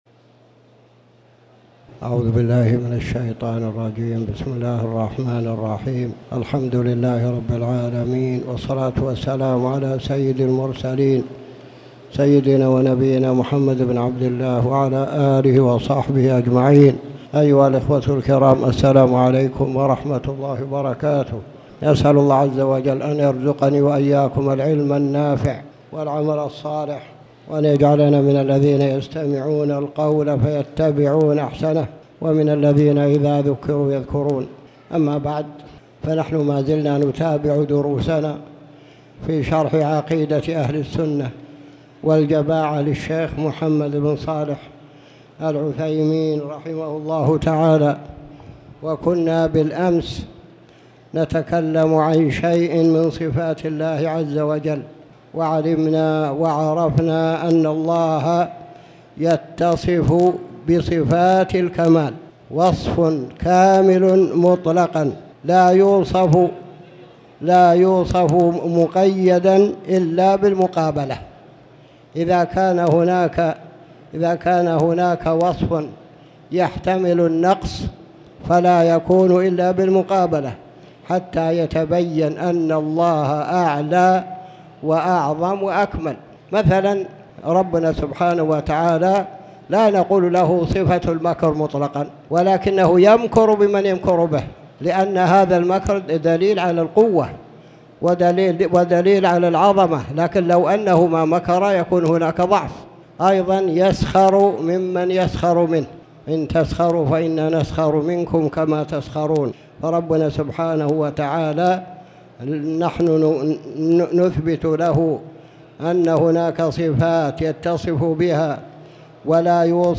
تاريخ النشر ١١ صفر ١٤٣٩ هـ المكان: المسجد الحرام الشيخ